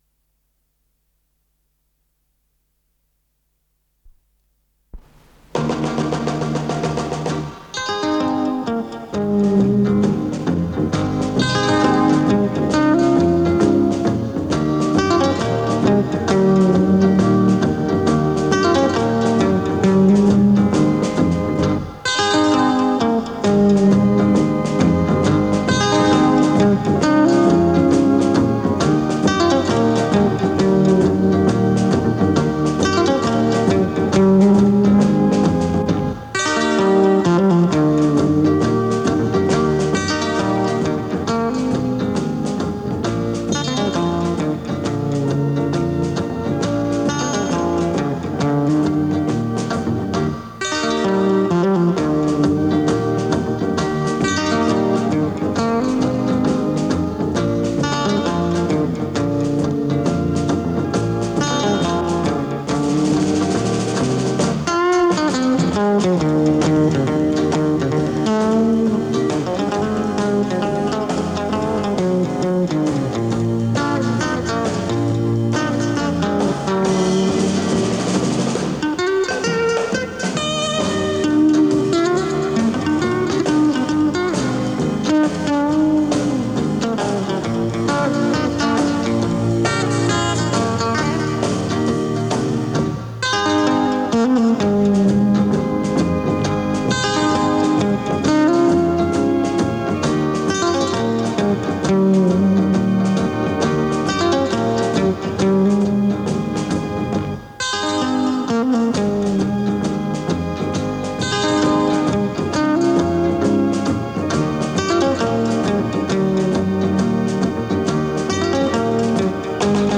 с профессиональной магнитной ленты
Скорость ленты38 см/с
ВариантМоно